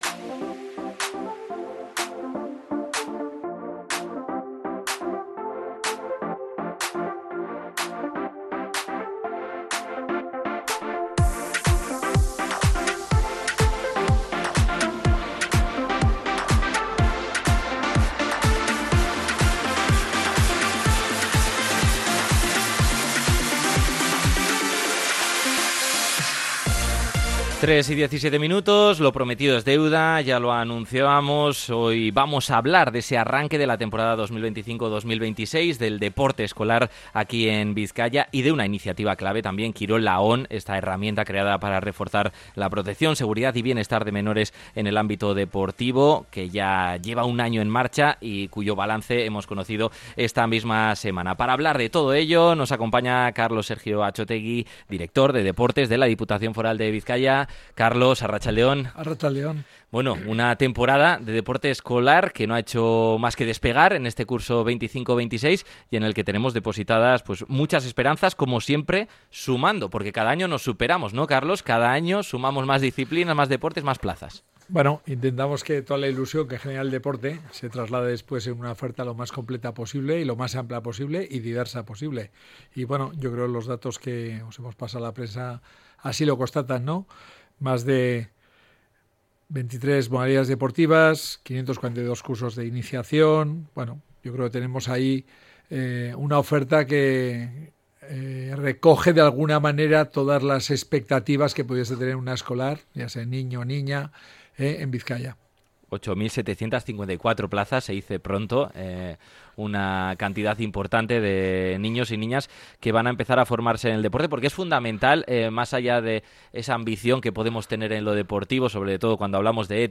Hablamos con Carlos Sergio, director de Deportes de la Diputación de Bizkaia, en el comienzo de la temporada 25-26 en el deporte escolar